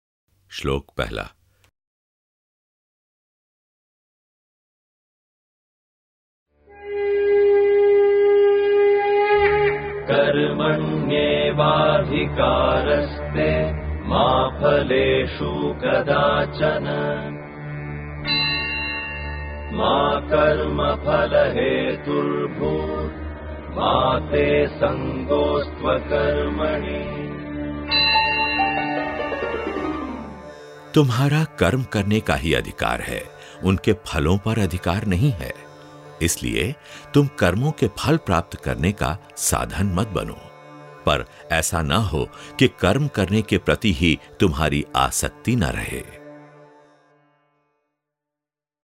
My voice ranges from middle aged to senior.
Deep, warm, soft, soothing, smooth, voice.
Sprechprobe: eLearning (Muttersprache):